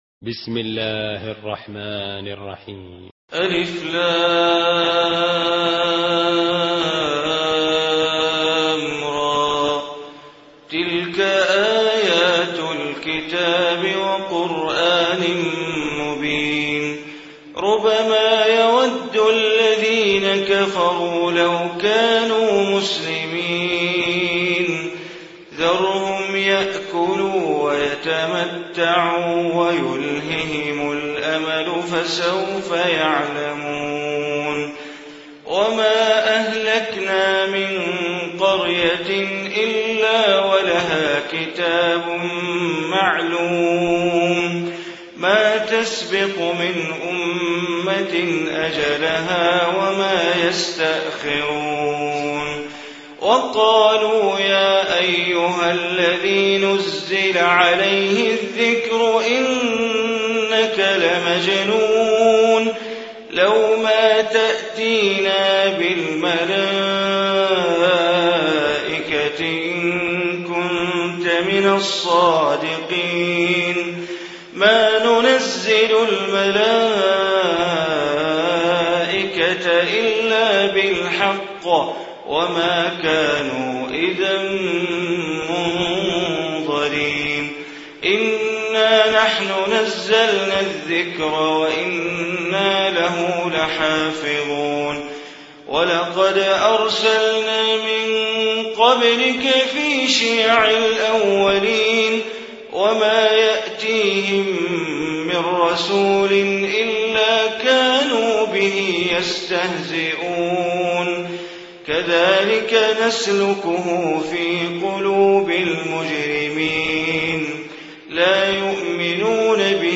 Surah Hijr Recitation by Sheikh Bandar Baleela
Surah Hijr, listen online mp3 tilawat / recitation in Arabic, recited by Imam e Kaaba Sheikh Bandar Baleela.